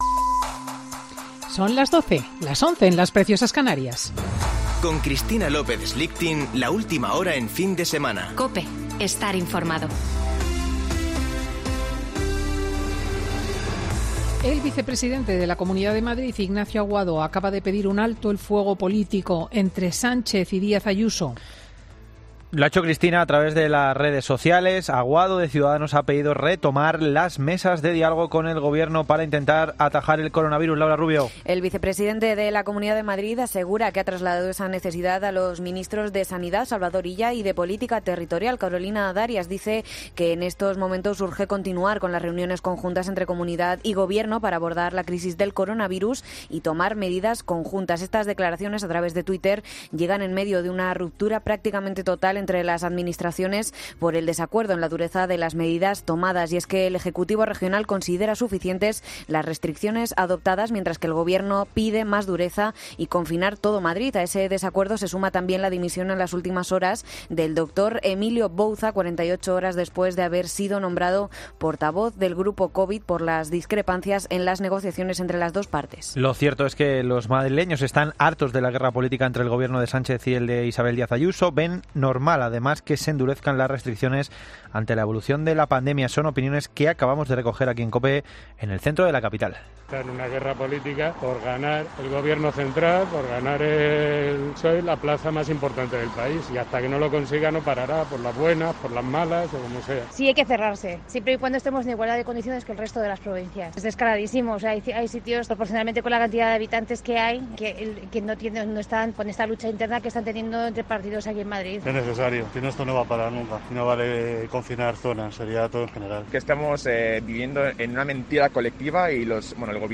Boletín de noticias COPE del 27 de septiembre de 2020 a las 12.00 horas